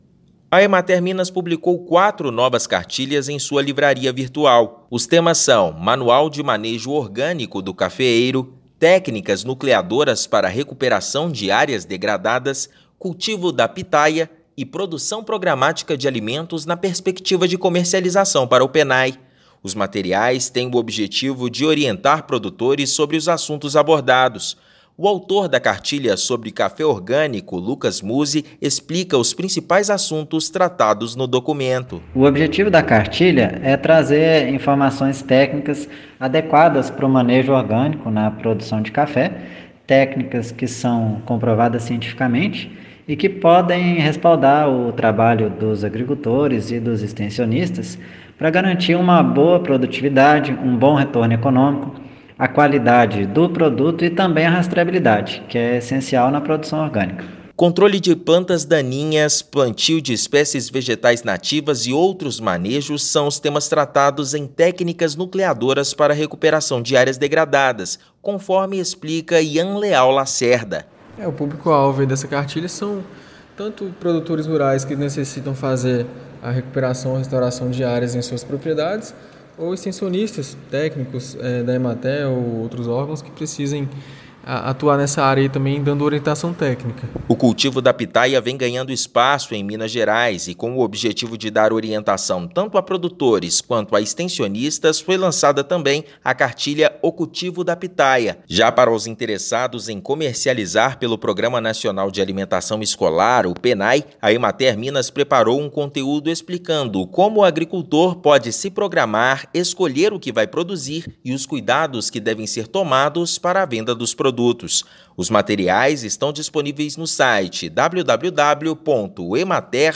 [RÁDIO] Cartilhas da Emater orientam agricultores sobre a produção da pitaia e outras culturas
As publicações gratuitas, disponível na livraria virtual, trazem informações técnicas de manejo e cultivo. Ouça matéria de rádio.